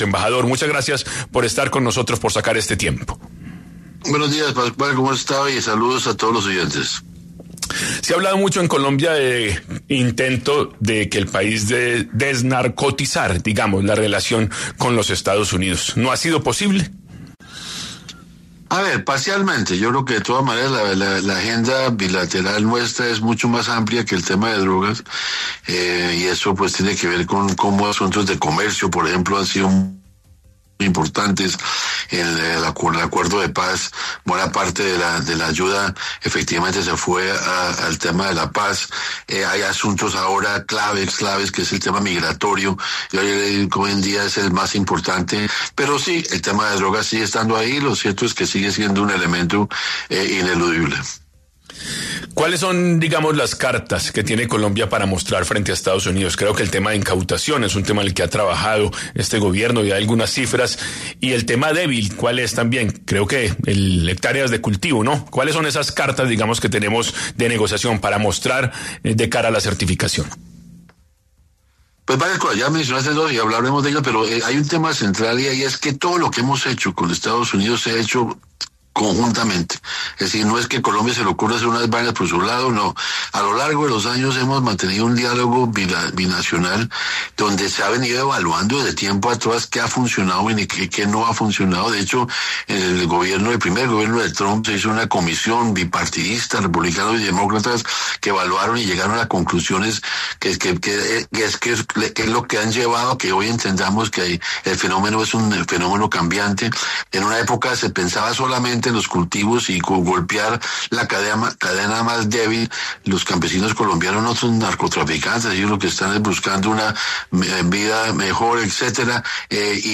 En 10AM de Caracol Radio estuvo Daniel García Peña, embajador de Colombia en los Estados Unidos, para hablar sobre qué pasará con la certificación antidrogas que entrega el Gobierno de EE.UU.